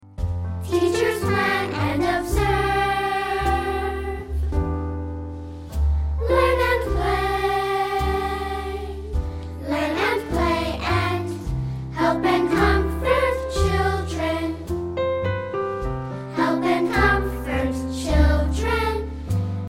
Children's Song Lyrics and Sound Clip